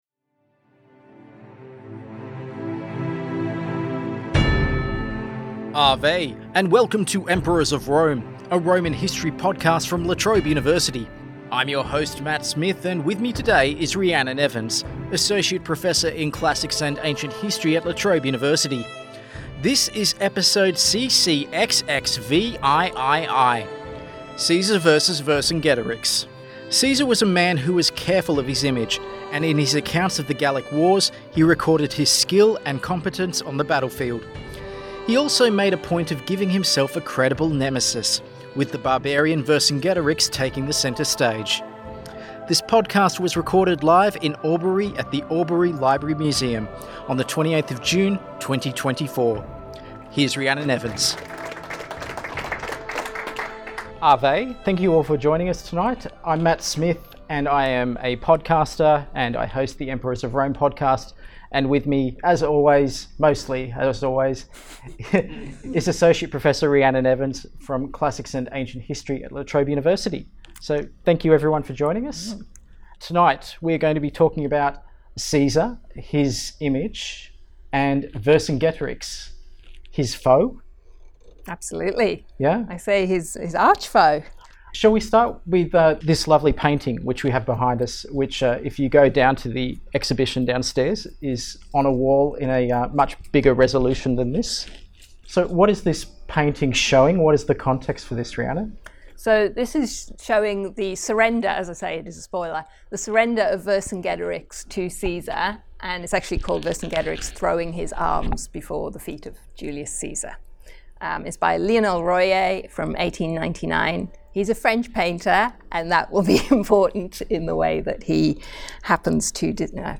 He also made a point of giving himself a credible nemesis, with the barbarian Vercingetorix taking the centre stage. Recorded live in Albury at the Albury LibraryMuseum on 24th June, 2024.